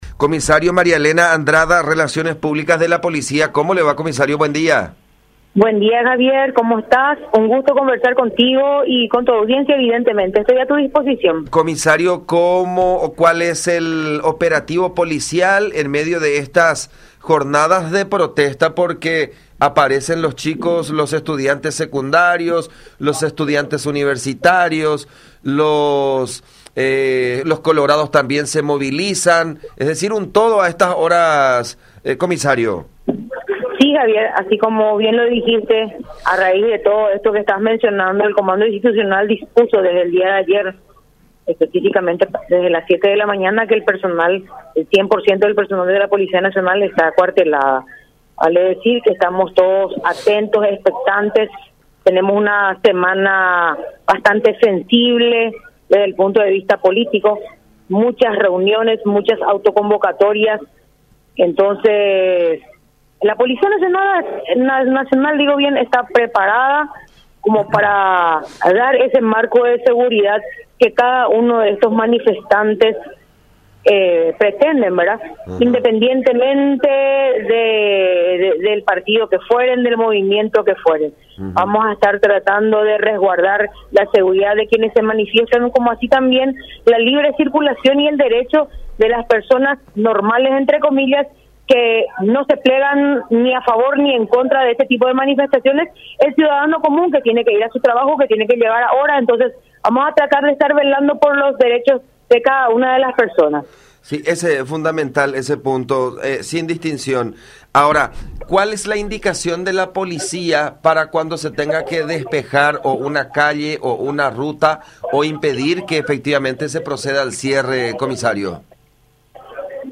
“Estamos para dar toda la seguridad que se precisa para estas movilizaciones que se van a dar hoy”, aseguró en contacto con La Unión.